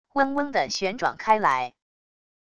嗡嗡的旋转开来wav音频